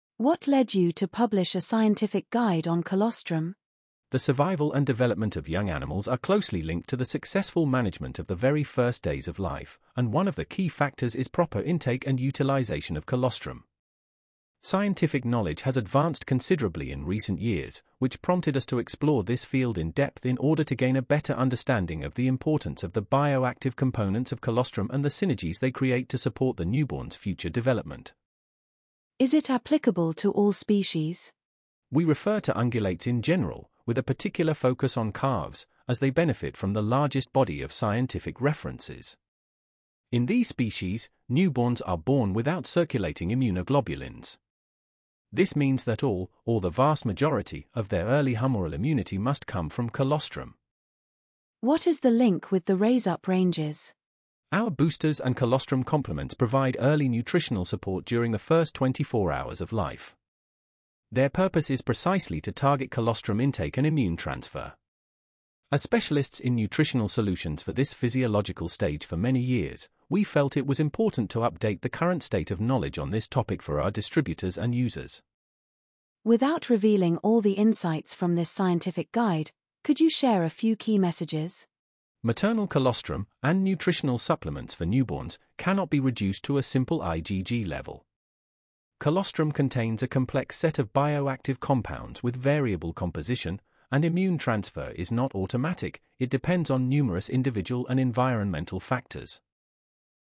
ScientificGuide-Colostrum-Interview.mp3